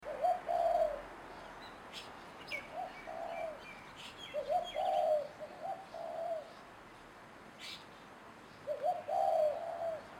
Spotted Dove
Streptopelia chinensis